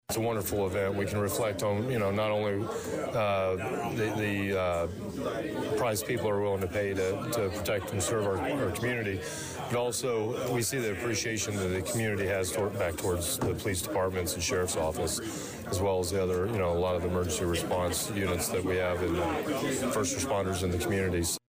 Danvillle Police Chief Christopher Yates stated prior to Tuesday (May 7th) night’s City Council meeting that this is always a humbling ceremony.